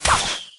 CR_archer_tower_fire_05.mp3